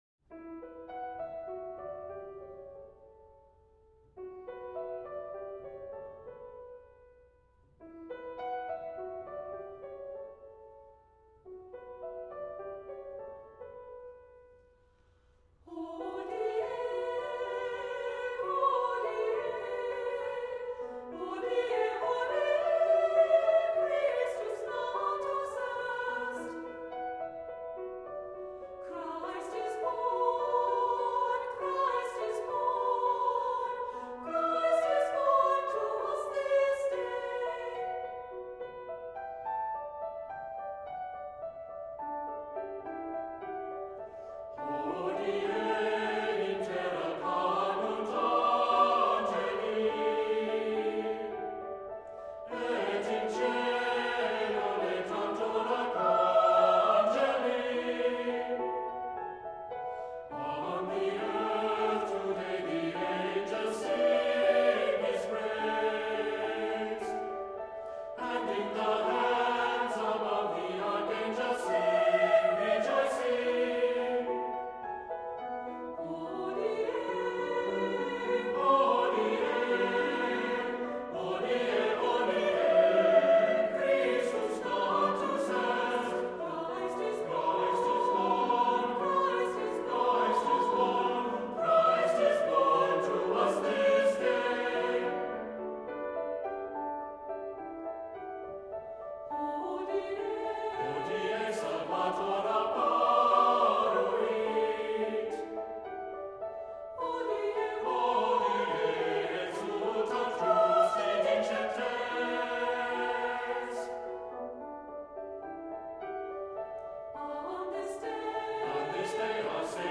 Accompaniment:      Piano, Harp;Percussion
Music Category:      Choral